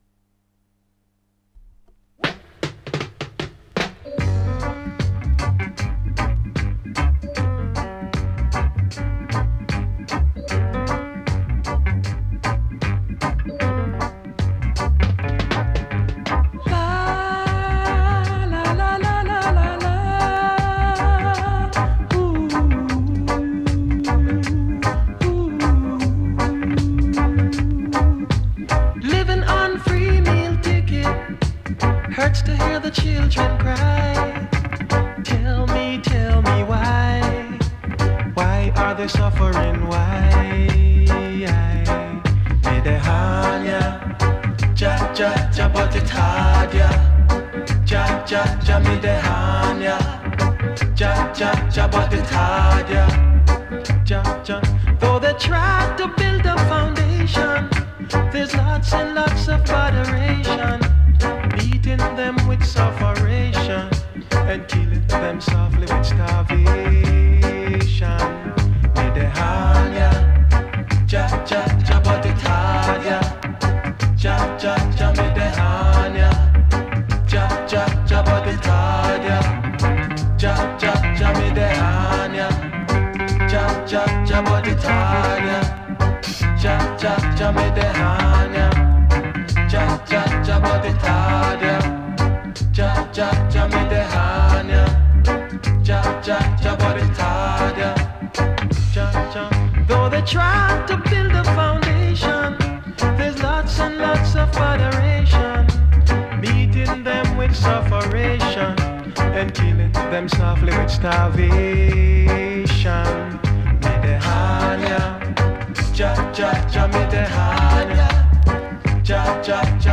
strictly roots music for conscious people